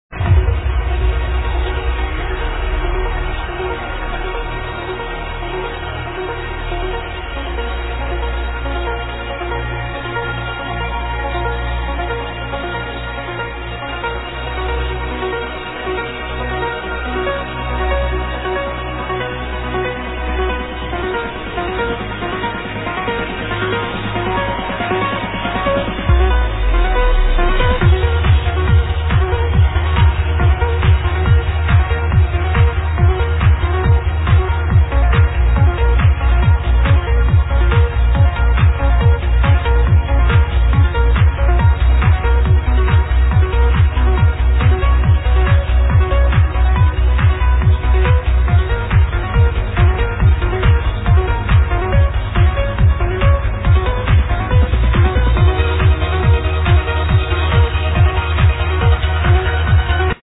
Simple Synth Uknown